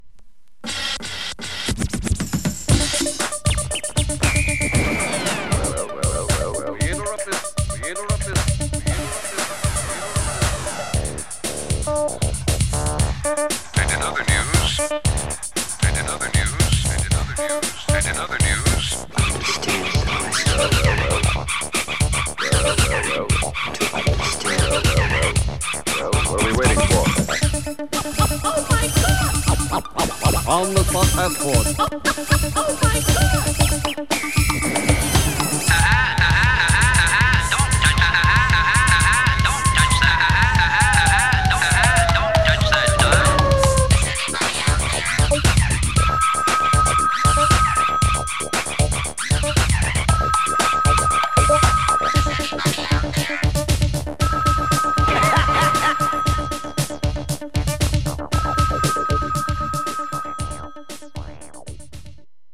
◇薄いスリキズでチリつく箇所あります